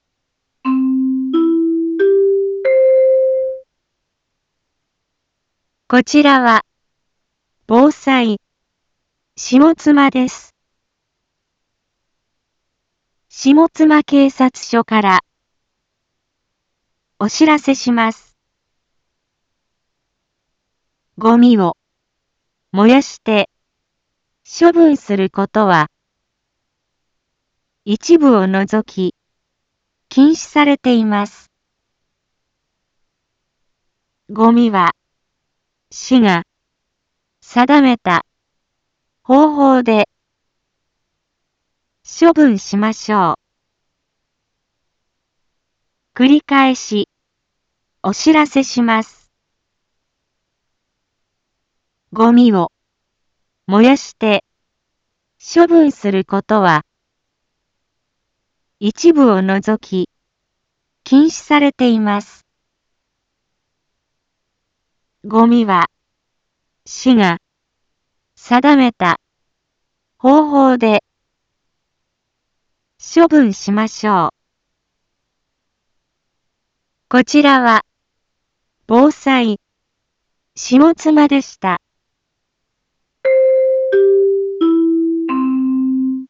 一般放送情報
Back Home 一般放送情報 音声放送 再生 一般放送情報 登録日時：2023-05-25 10:01:30 タイトル：ごみの野焼き禁止（啓発放送） インフォメーション：こちらは、防災、下妻です。